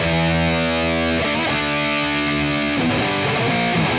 Index of /musicradar/80s-heat-samples/120bpm
AM_RawkGuitar_120-E.wav